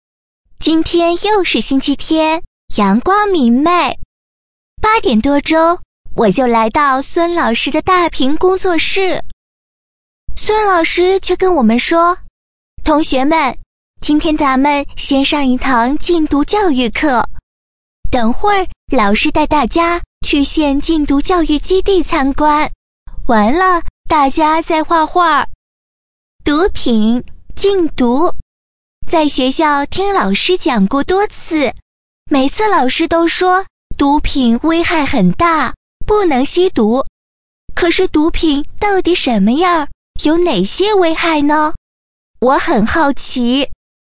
【女36号模仿】（女童音）放飞绿色梦想
【女36号模仿】（女童音）放飞绿色梦想.mp3